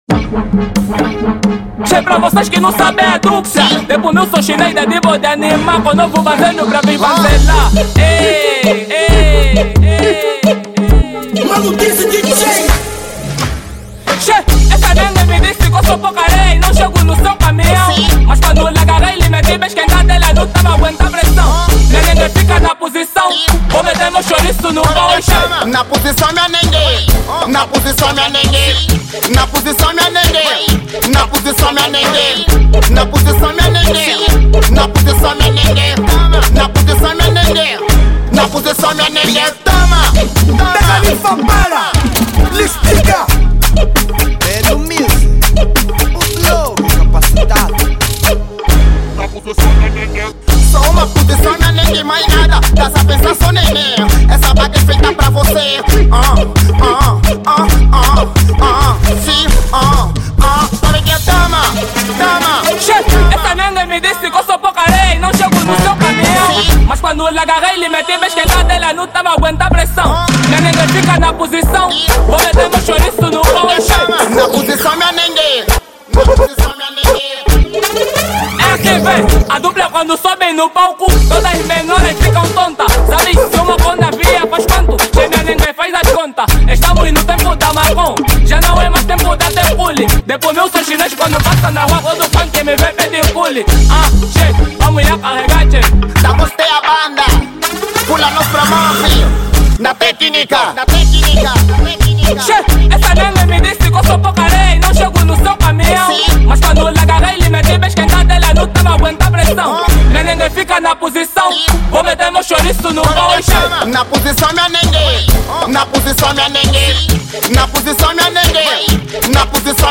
Gênero : Afro house